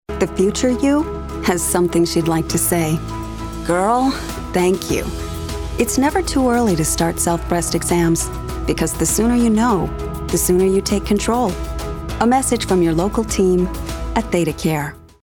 Radio
thedacare-never-too-early_a_15radio.mp3